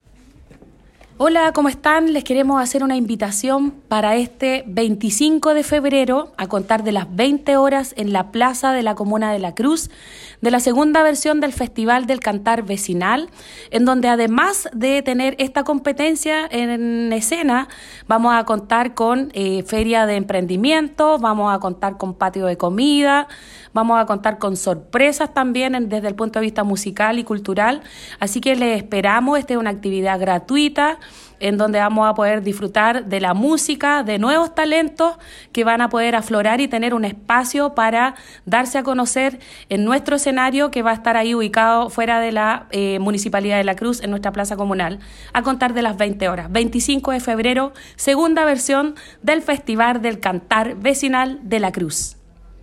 Desde el municipio, la alcaldesa Filomena Navia Herrera, invitó a toda la comunidad crucina y de comunas de la provincia para disfrutar de este espacio musical, donde vecinos y vecinas podrán mostrar sus talentos:
cuna-alcaldesa-festival-cantar-la-cruz.mp3